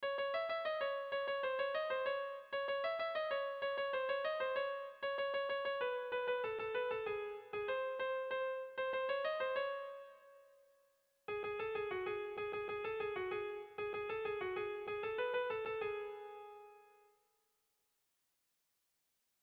Dantzakoa
ABD1D2